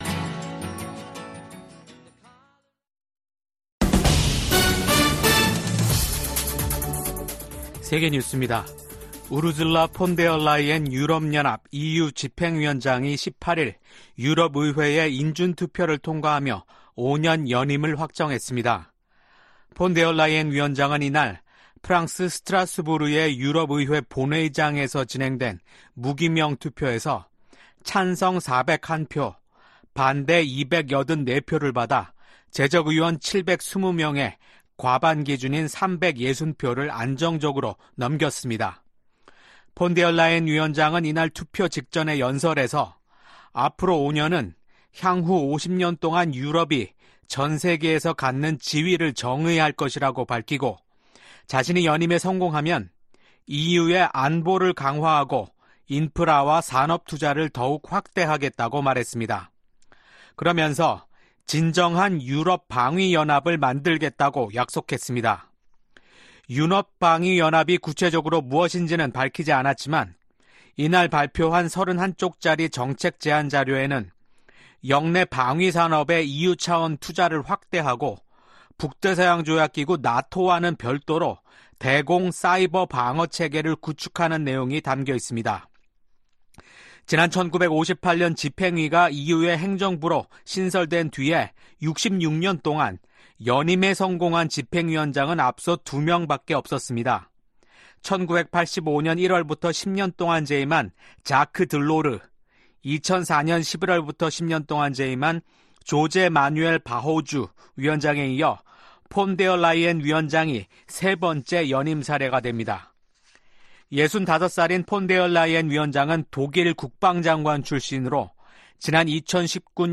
VOA 한국어 아침 뉴스 프로그램 '워싱턴 뉴스 광장' 2024년 7월 19일 방송입니다. 미 중앙정보국(CIA) 출신의 대북 전문가가 미 연방검찰에 기소됐습니다. 북한이 신종 코로나바이러스 감염증 사태가 마무리되면서 무역 봉쇄를 풀자 달러 환율이 고공행진을 지속하고 있습니다. 최근 북한을 방문한 유엔 식량농업기구 수장이 북한이 농업 발전과 식량 안보에서 큰 성과를 냈다고 주장했습니다.